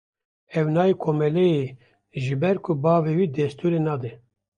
Pronounced as (IPA)
/bɛɾ/